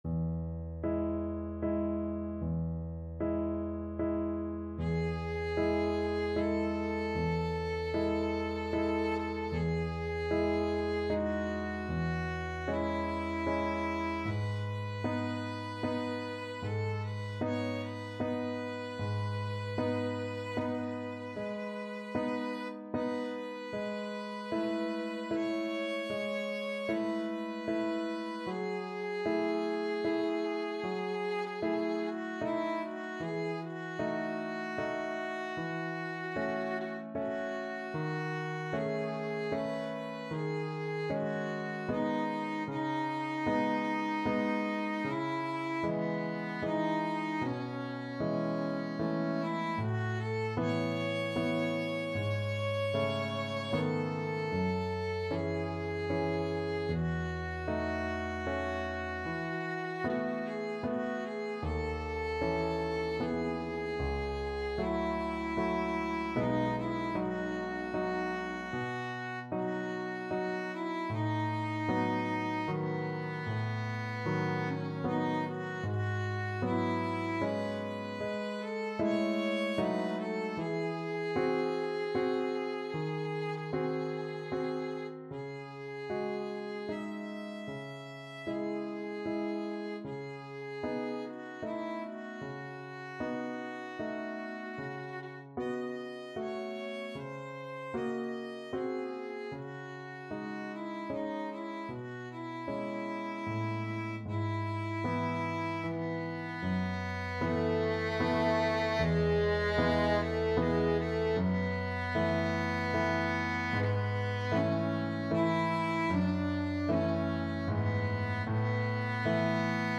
Adagio assai =76
3/4 (View more 3/4 Music)
Classical (View more Classical Violin Music)